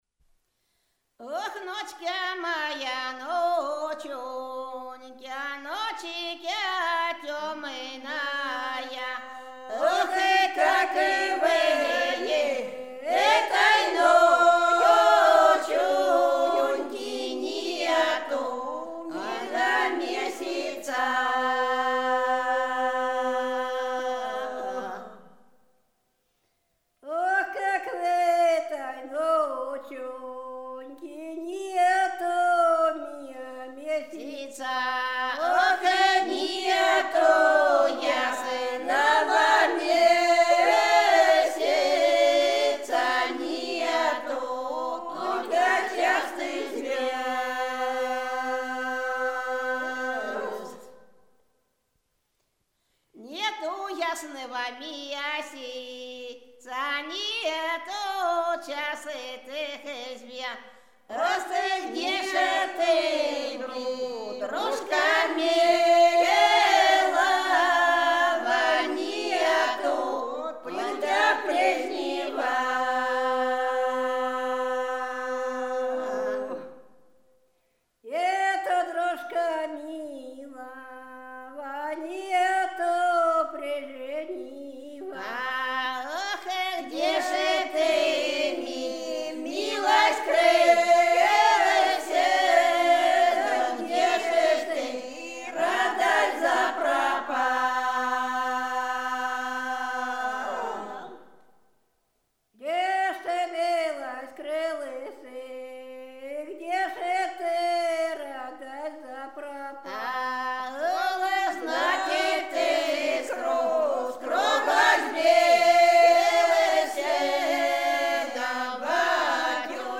Рязань Секирино «Ох, ночкя моя ночунькя», лирическая.